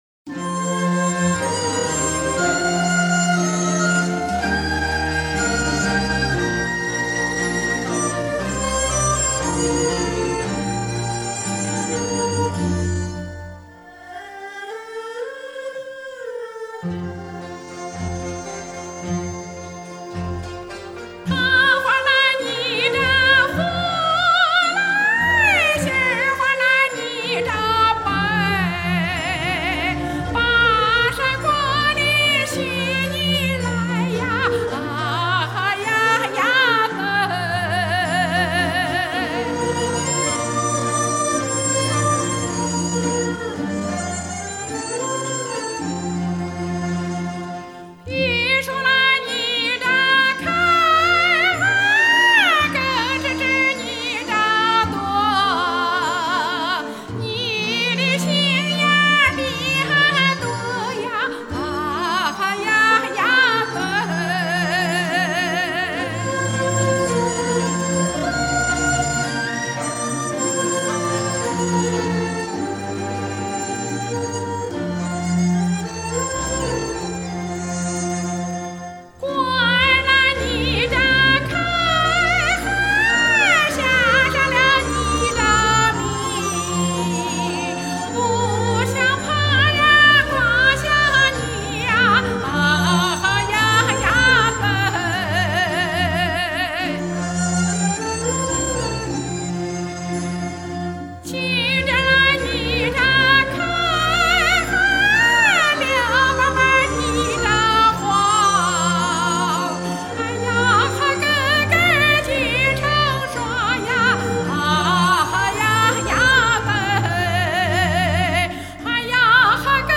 左权民歌